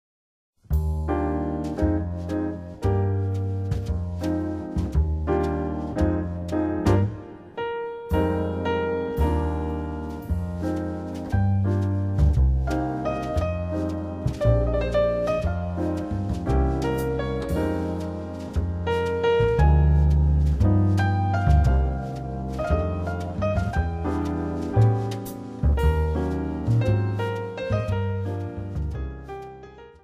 將古典大師的作品改編成爵士三重奏的型式，讓高雅的 古典曲目增添了輕快寫意的風味